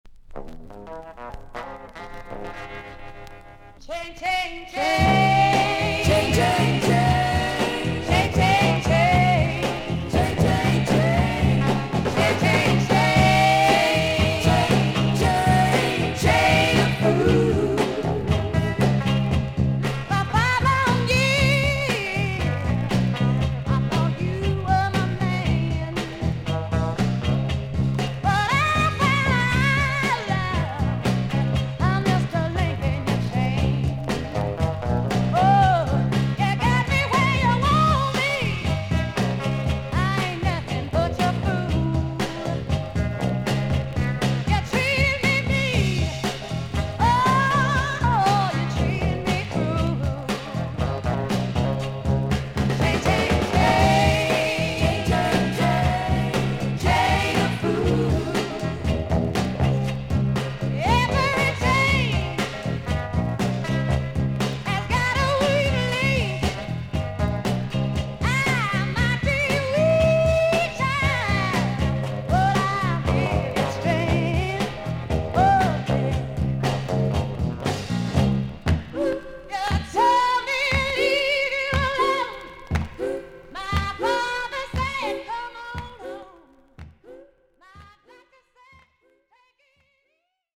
女性R&B/ソウル・シンガー。